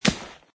plasticgrass2.mp3